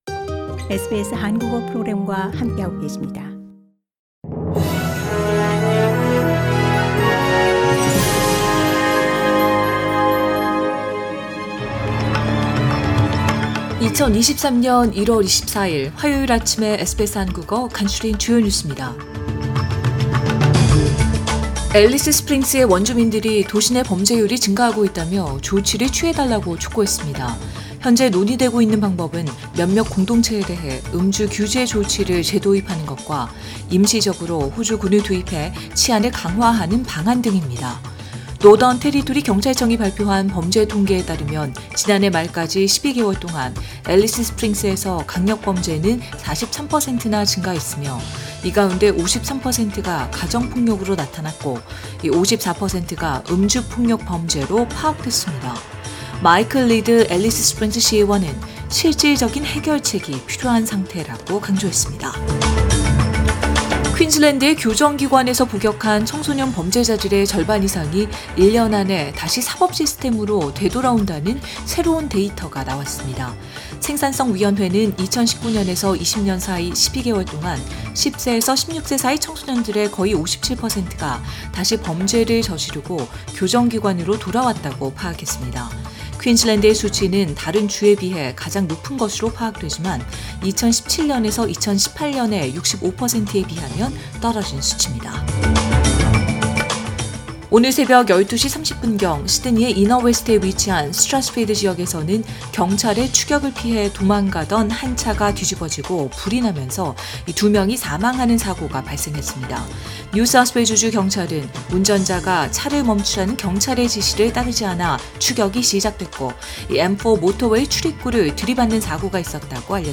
SBS 한국어 아침 뉴스: 2022년 1월 24일 화요일